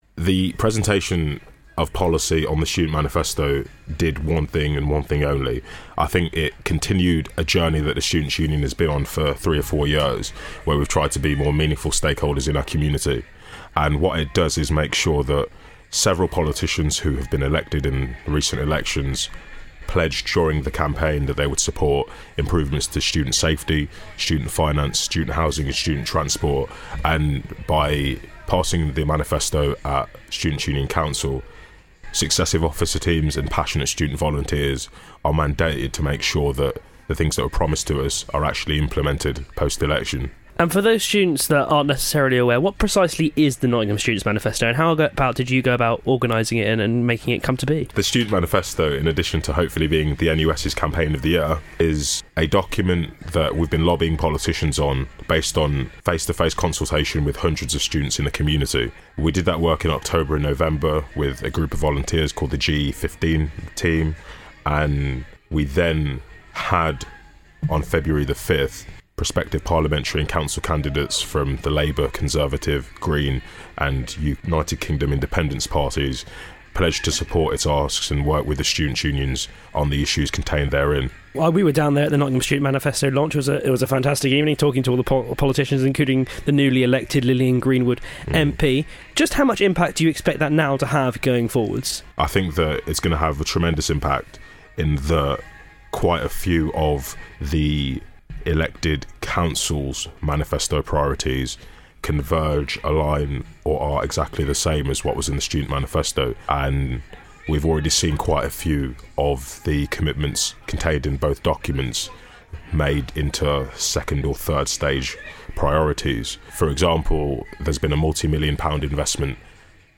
URN Interviews